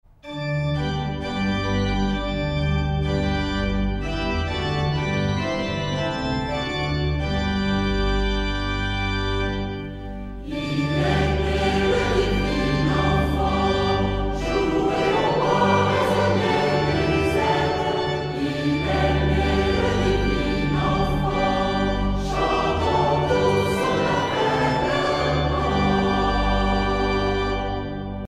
Genre strophique Artiste de l'album Saint-Serge (chorale)
Pièce musicale éditée